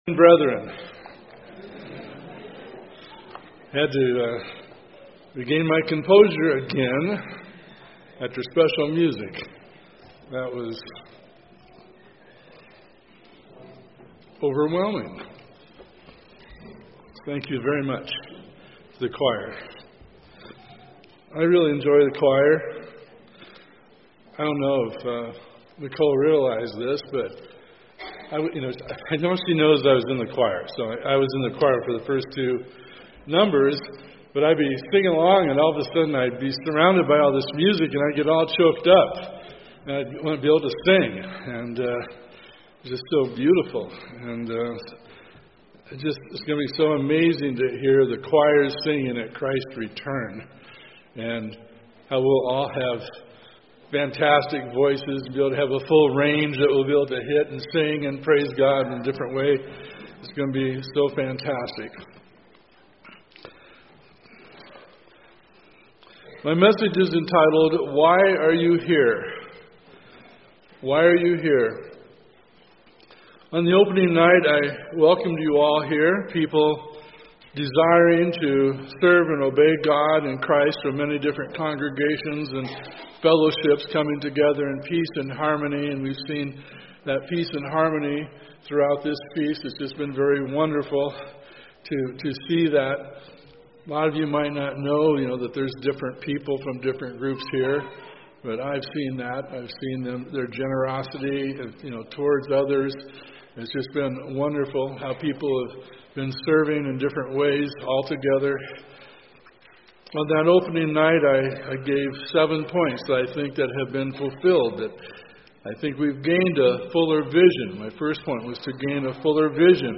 This sermon was given at the Glacier Country, Montana 2018 Feast site.